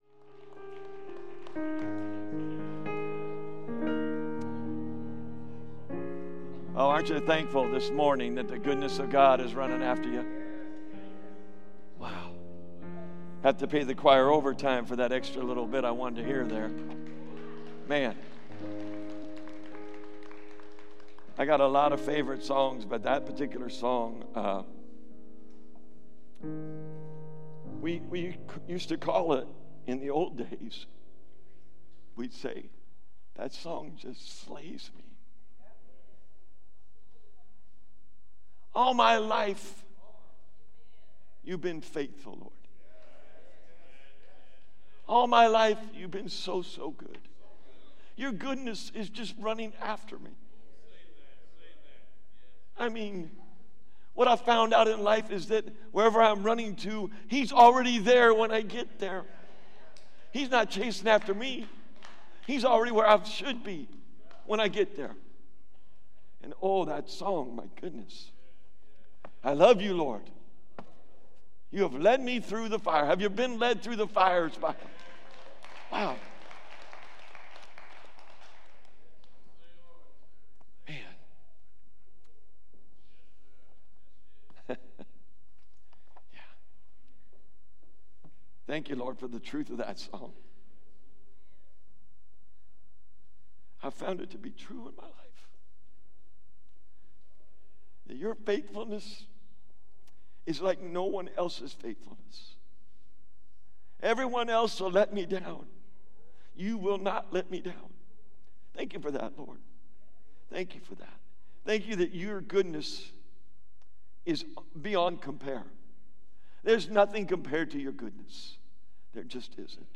Valley Bible Church Sermons (VBC) - Hercules, CA